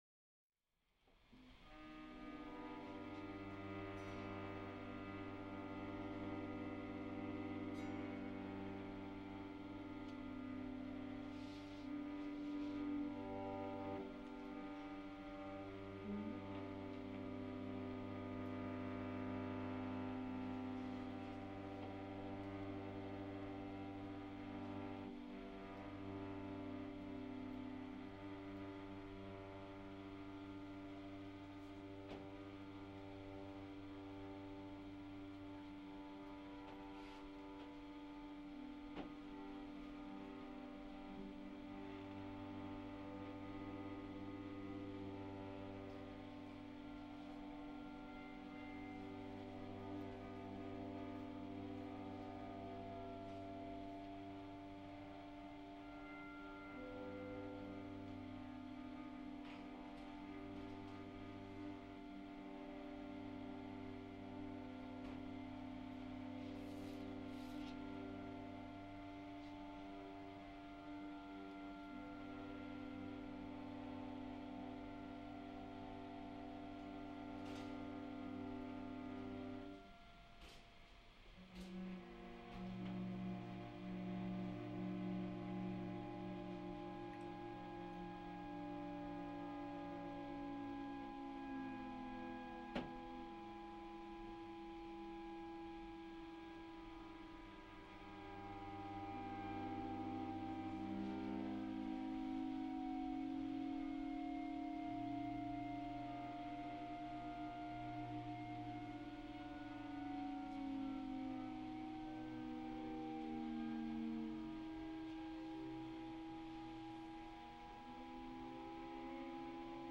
LIVE PERFORMANCE
lap steel guitar
cello
trombone